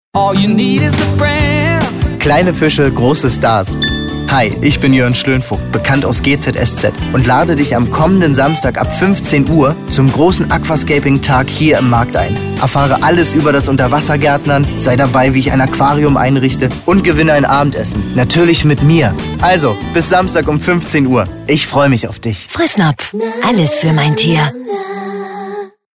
Jörn Schönvoigt für Fressnapf – POS Werbung – Instoreradio